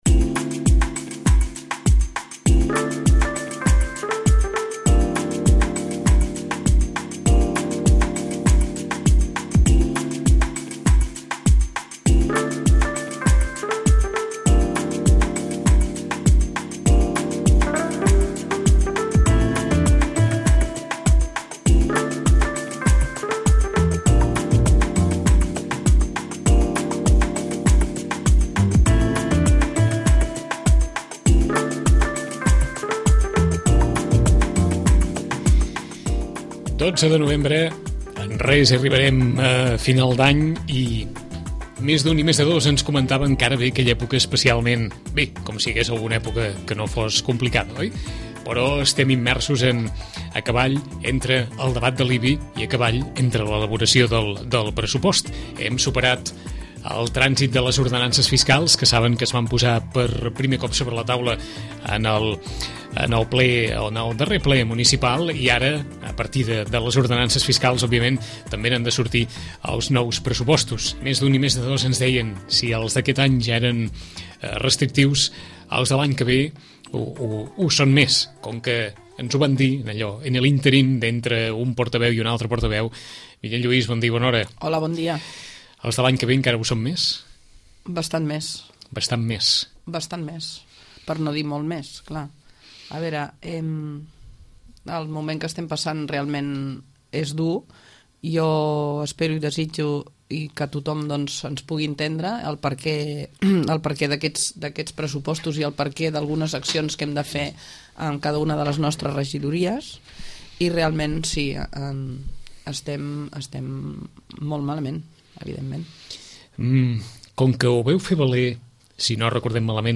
Ràdio Maricel. Emissora municipal de Sitges. 107.8FM. Escolta Sitges.
Amb la portaveu de Sitges Grup Independent, Vinyet Lluís, conversem sobre l’actualitat política, centrada en la revisió cadastral, el pressupost del 2013, i la situació de les infraestructures educatives i esportives, amb especial atenció a l’estudi que s’està realitzant sobre el consorci del Club Natació.